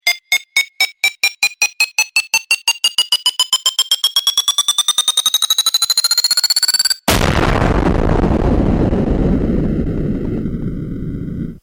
Bomb - Бомба с таймером
Отличного качества, без посторонних шумов.
353_bomba.mp3